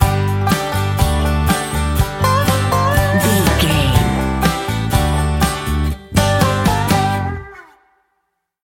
Ionian/Major
acoustic guitar
banjo
bass guitar
drums